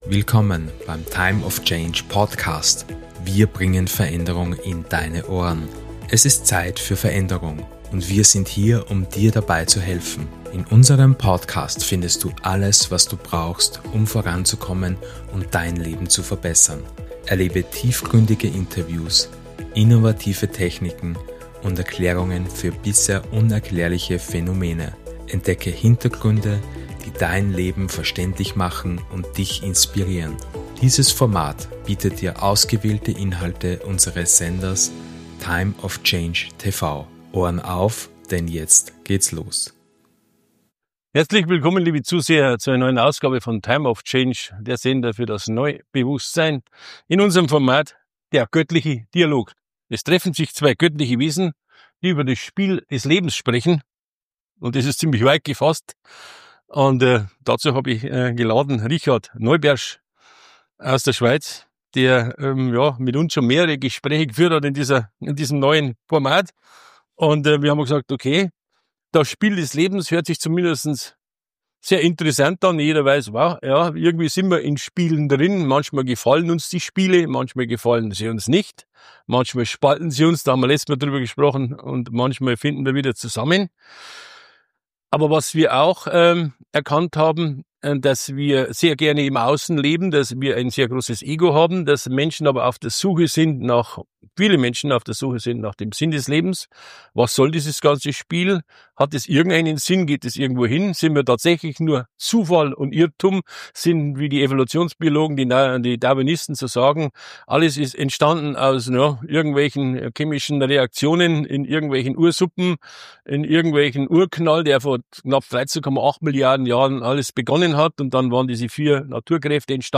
In dieser tiefgründigen Fortsetzung unserer beliebten Interviewreihe erfährst Du mehr über das komplexe "Spiel des Lebens". Entdecke, wie Du Deine verborgenen Talente entfalten und Dein volles Potenzial ausschöpfen kannst.